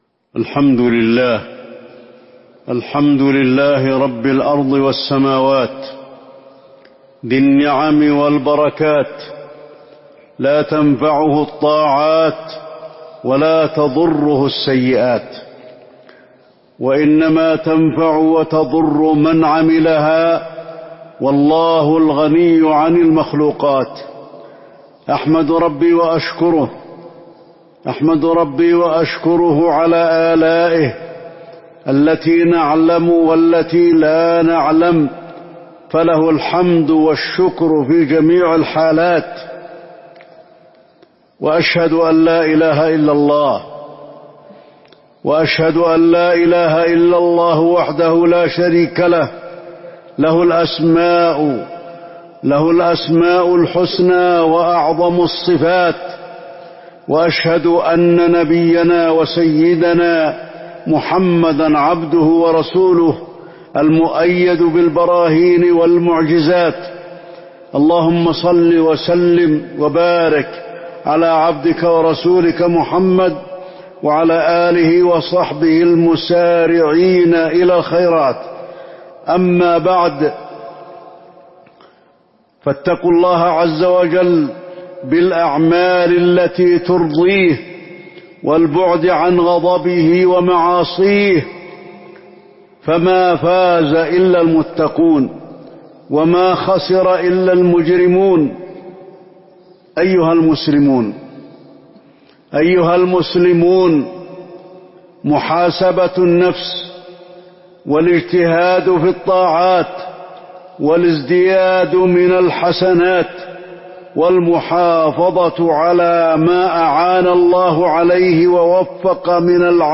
تاريخ النشر ١١ رمضان ١٤٤٢ هـ المكان: المسجد النبوي الشيخ: فضيلة الشيخ د. علي بن عبدالرحمن الحذيفي فضيلة الشيخ د. علي بن عبدالرحمن الحذيفي محاسبة النفس والاجتهاد في الطاعات